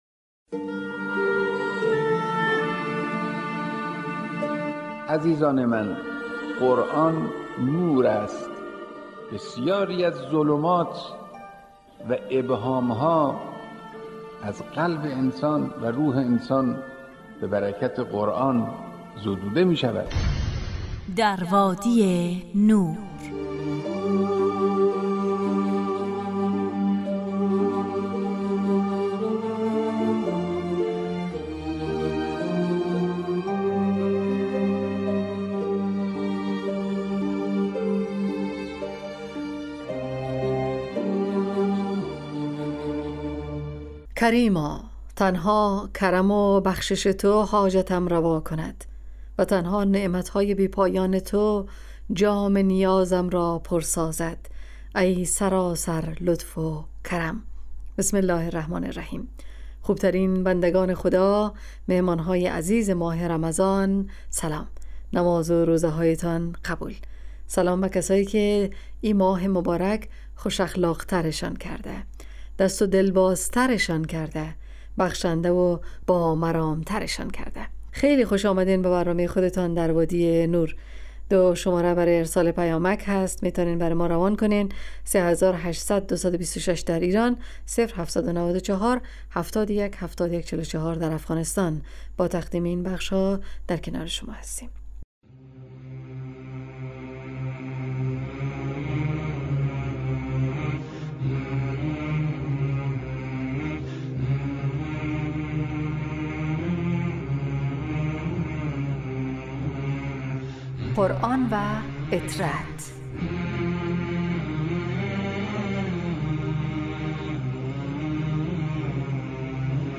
برنامه ای 45 دقیقه ای با موضوعات قرآنی
ایستگاه تلاوت